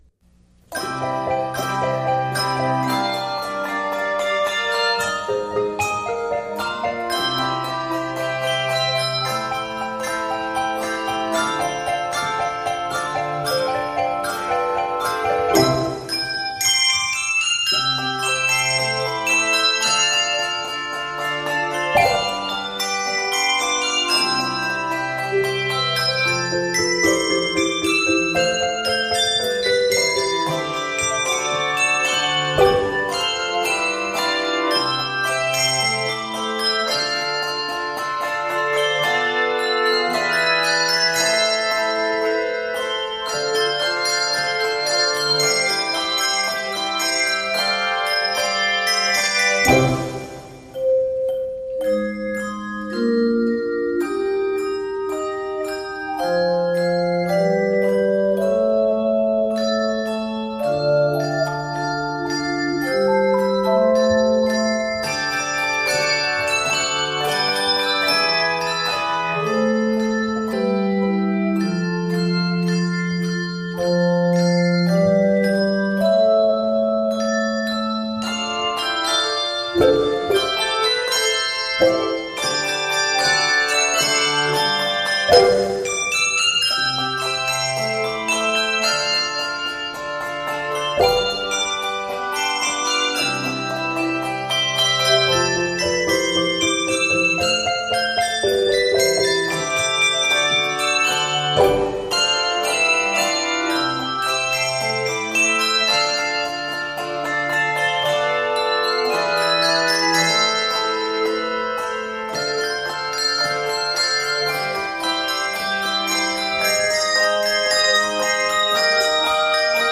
is written in C Major and is a bright 74 measures
N/A Octaves: 3-5 Level